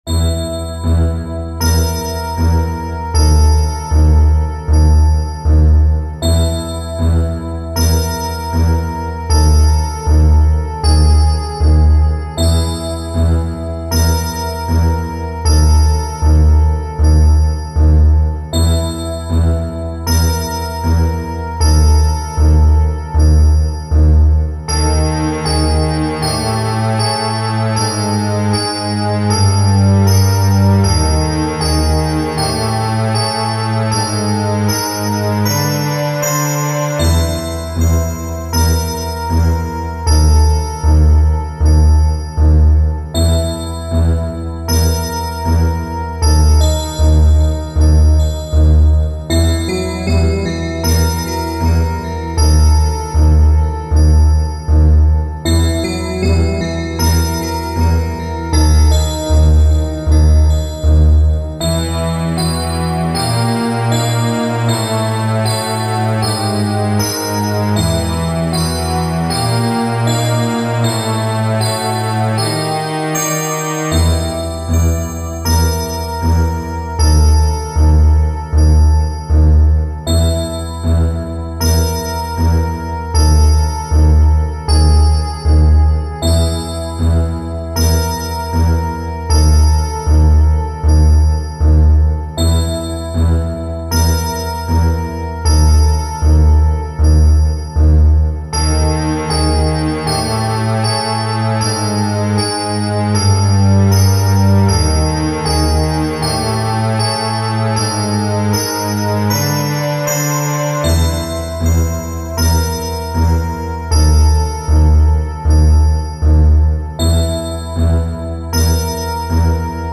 【用途/イメージ】　怪談　都市伝説　ダーク　不気味　不安　緊張
ストリングス　ベル